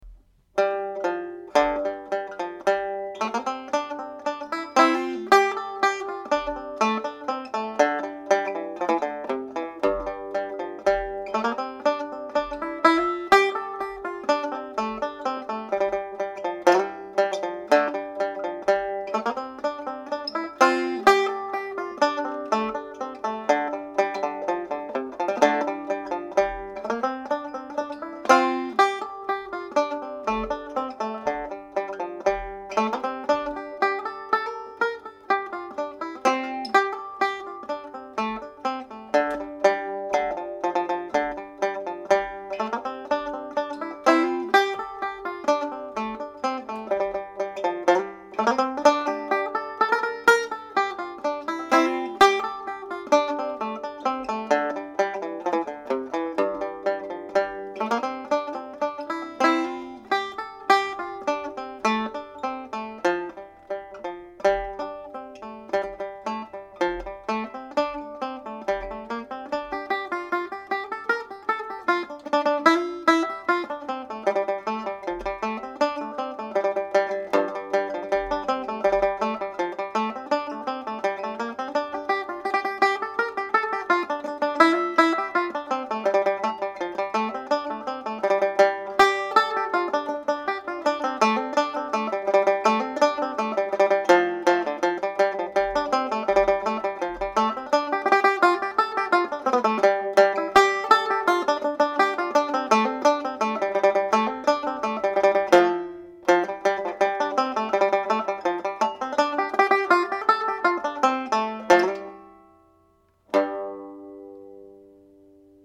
This is a hornpipe in the key of G model which is sometimes played as a reel.